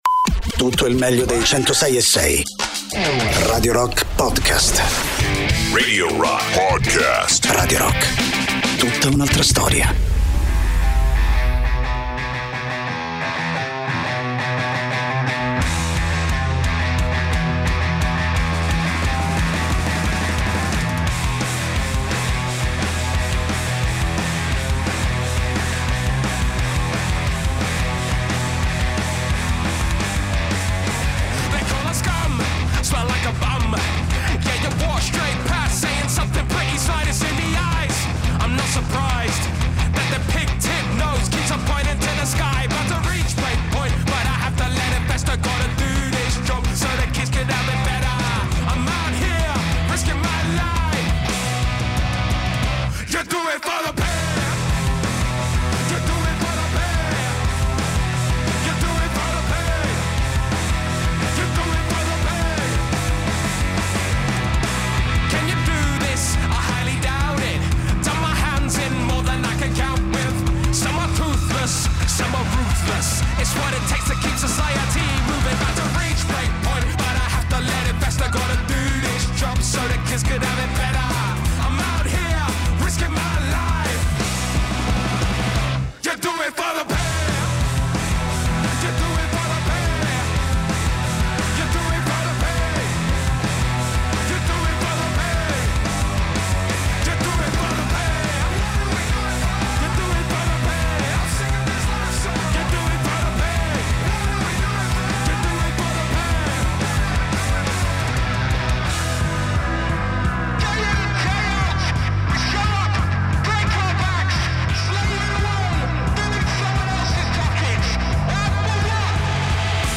Radio Rock FM 106.6 Interviste